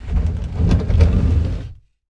SlidingBookcase